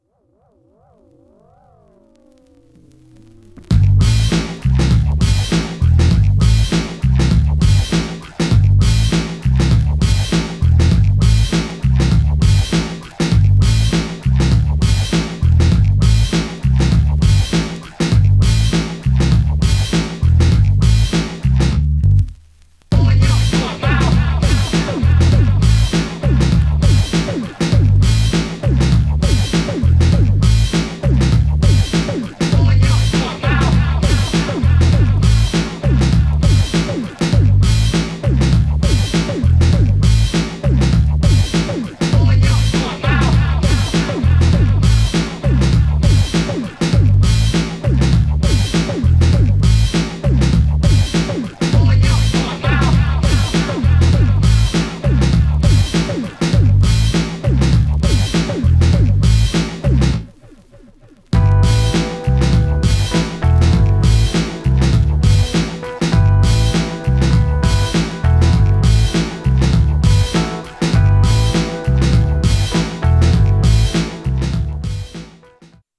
Styl: Drum'n'bass, Jungle/Ragga Jungle, Lounge